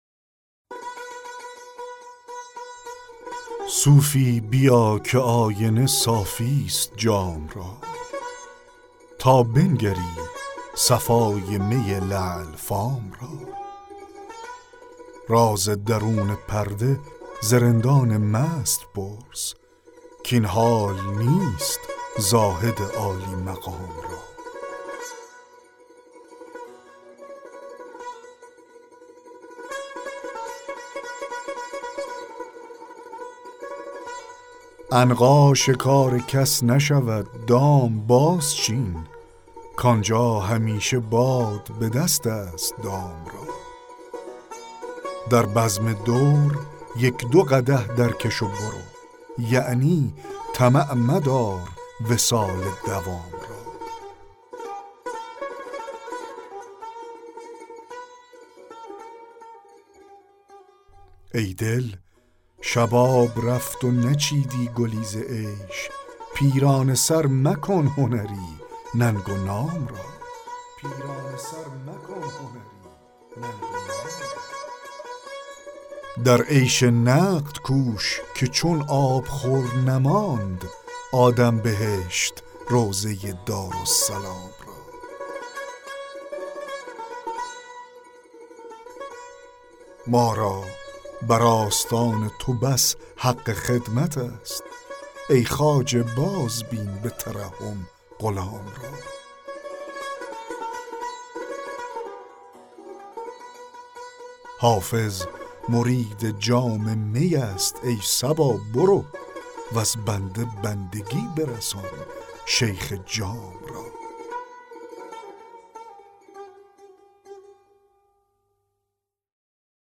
دکلمه غزل 7 حافظ